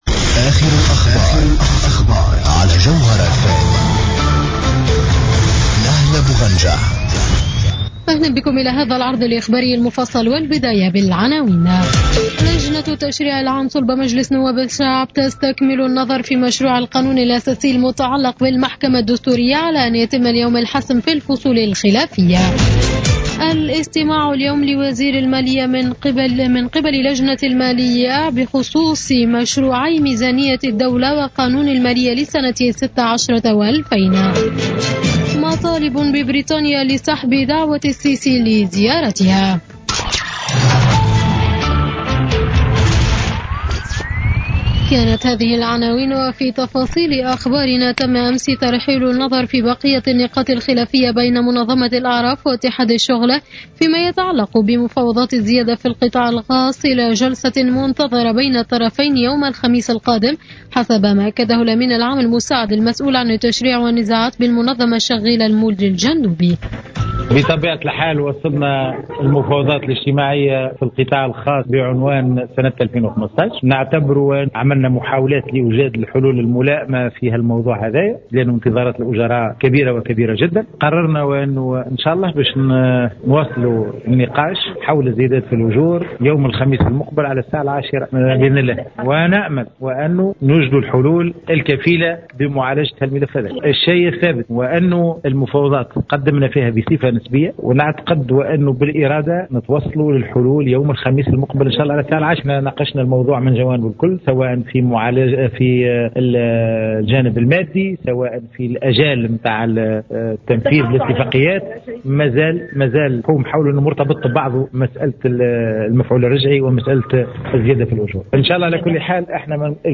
نشرة أخبار منتصف الليل ليوم الأربعاء 28 أكتوبر 2015